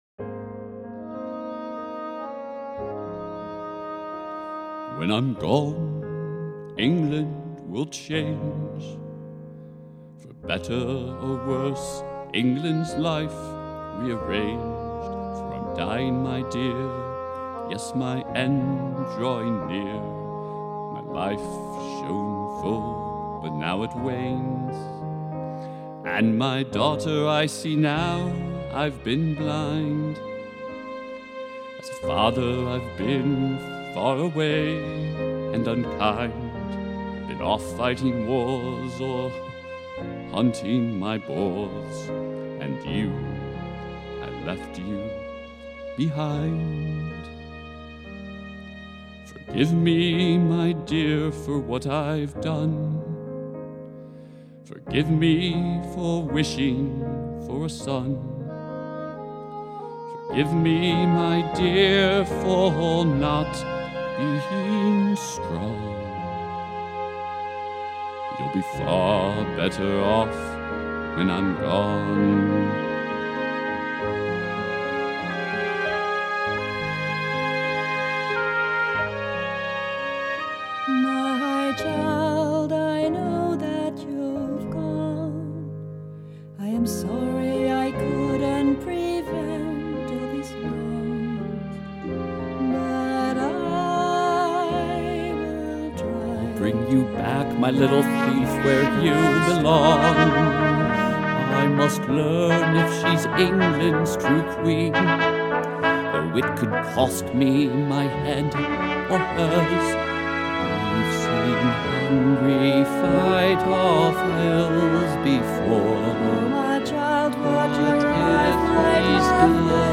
Forgive Me [Act I climax song--6+ singers